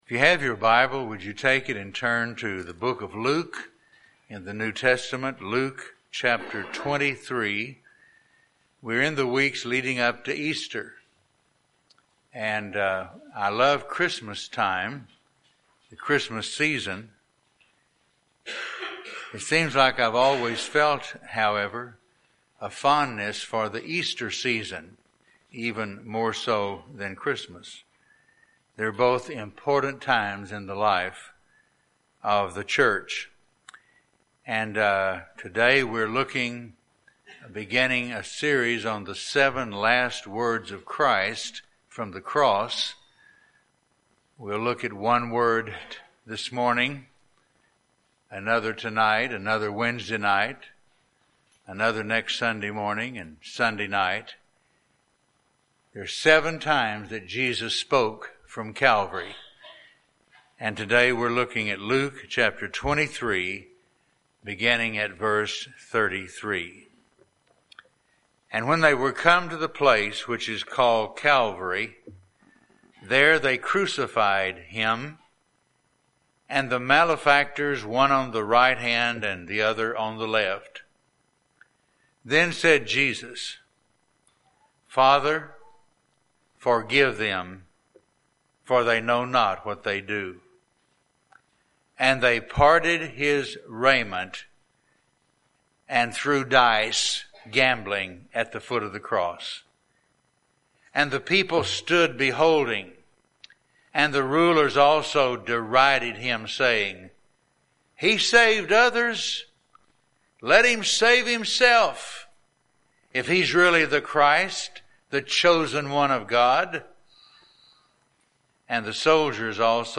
Morning message from Luke 23:33-38.